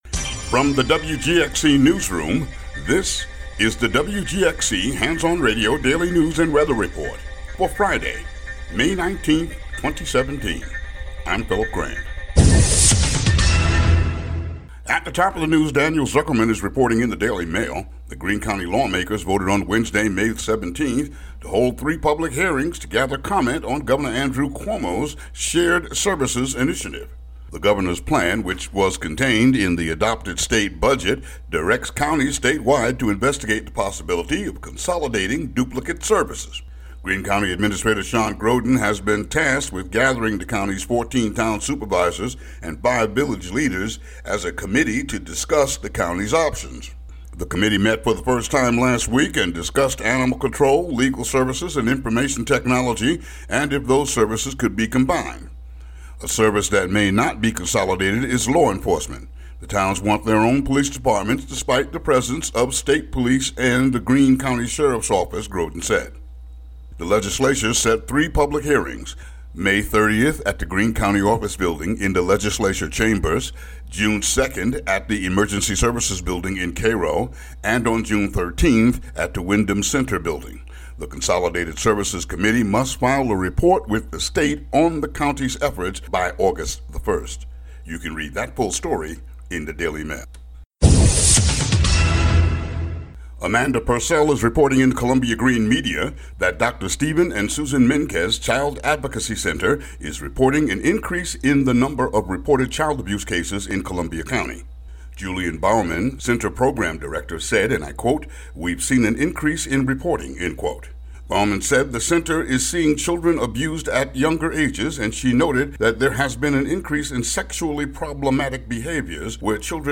WGXC daily headlines for May 19, 2017.